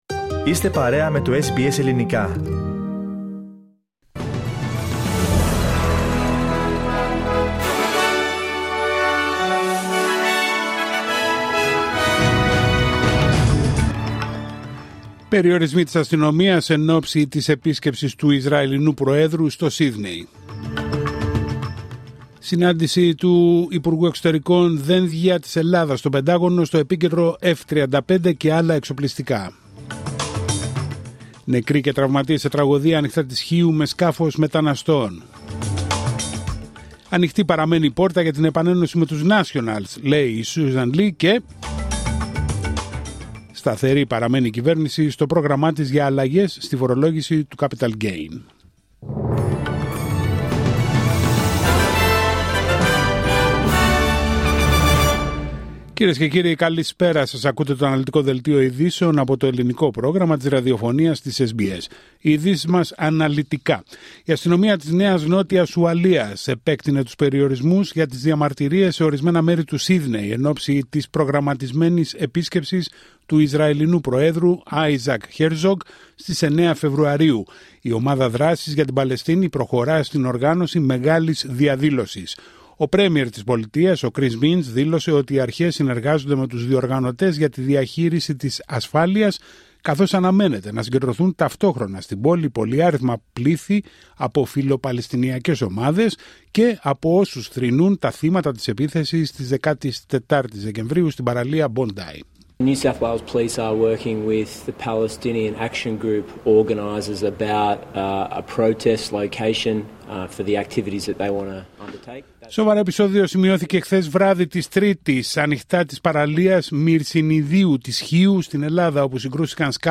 Δελτίο ειδήσεων Τετάρτη 4 Φεβ. 26